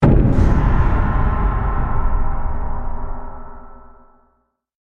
Download League of Legends Queue Pp sound effect for free.